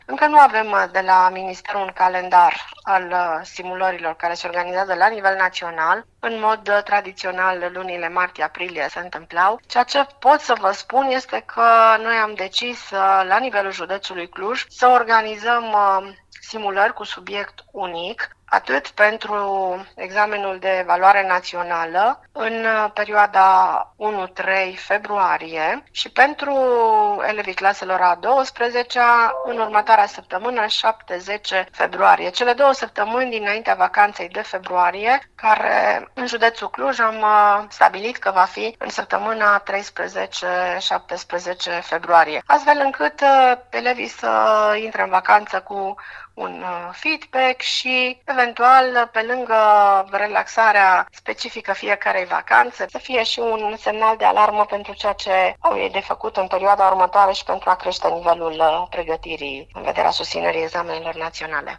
Șefa ISJ Cluj, Marinela Marc: